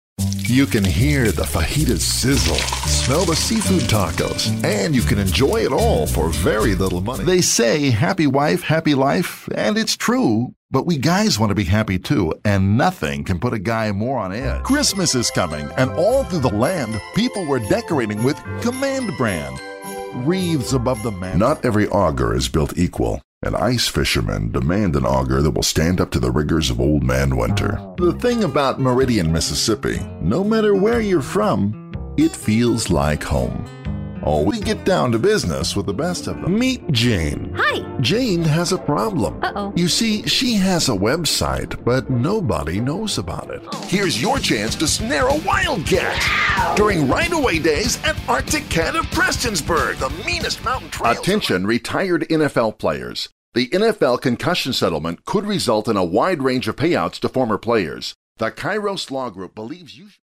Resonant, deep baritone voice with a warm delivery.
Commercials
Mid-western, west coast American English